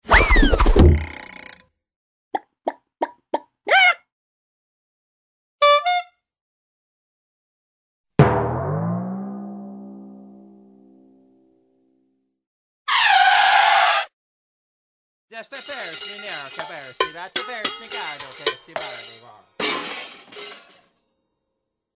音效-喜剧搞笑卡通动物游戏音效-学驰资源
音效介绍99个喜剧幽默滑稽搞笑卡通动物游戏音效。